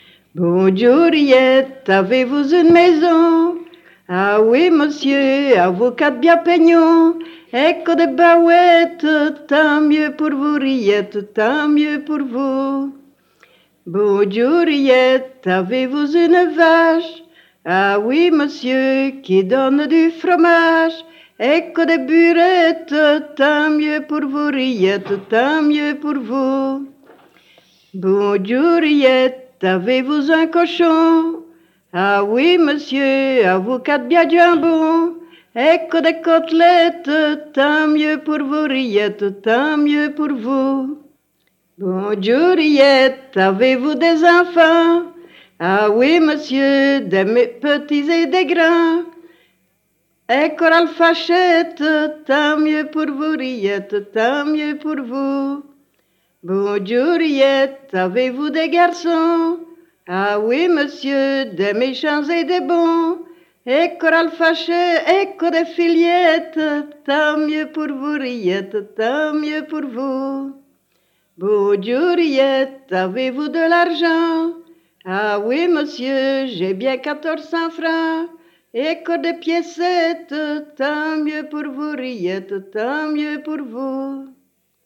Genre : chant
Type : chanson narrative ou de divertissement
Interprète(s) : Anonyme (femme)
Lieu d'enregistrement : Surice
Support : bande magnétique